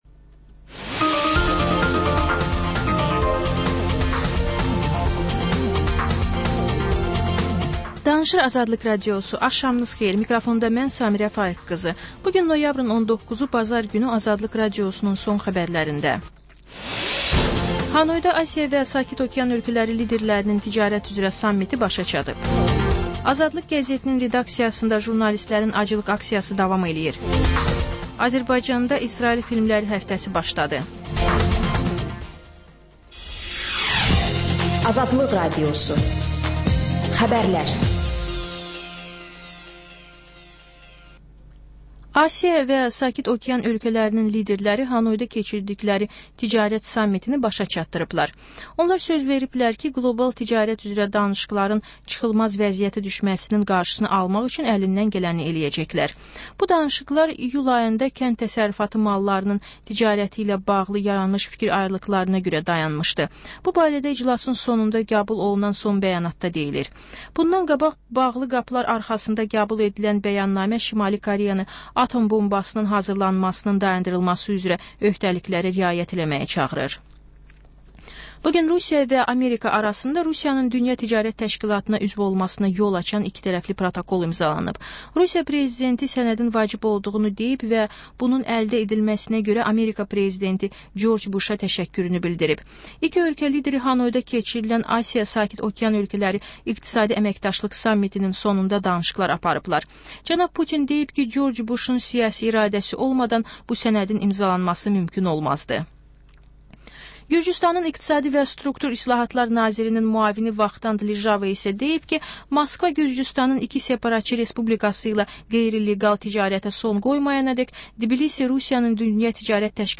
Xəbərlər, reportajlar, müsahibələr. İZ: Mədəniyyət proqramı.